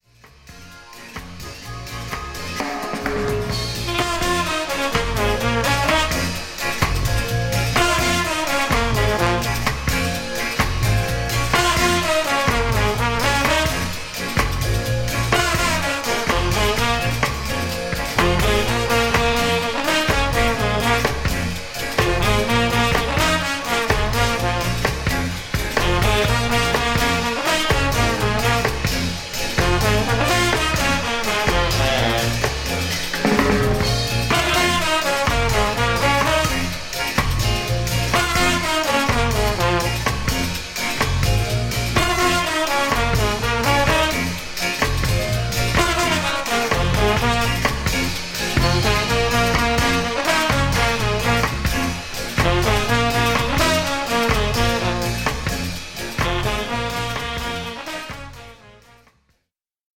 1995年のライブ・アルバム。